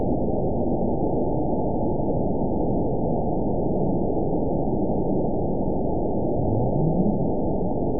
event 922703 date 03/14/25 time 21:31:34 GMT (1 month, 2 weeks ago) score 9.57 location TSS-AB04 detected by nrw target species NRW annotations +NRW Spectrogram: Frequency (kHz) vs. Time (s) audio not available .wav